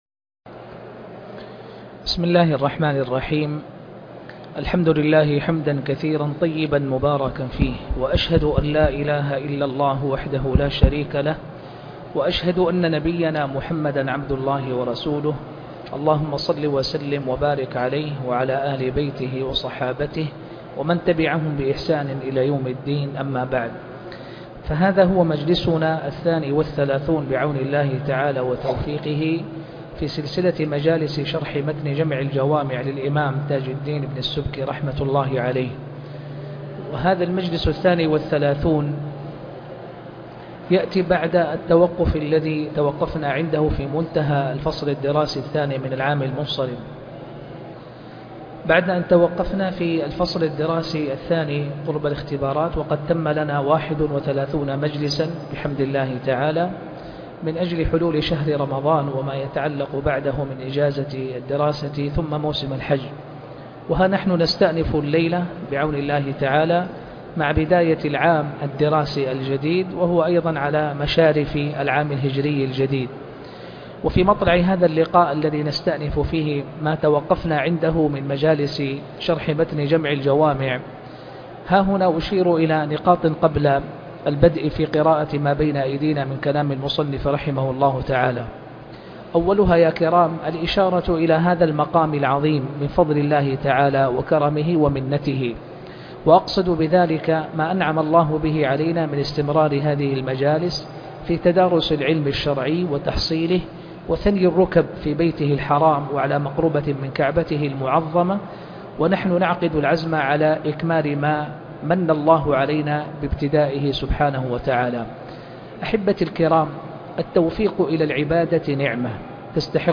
شرح جمع الجوامع الدرس 30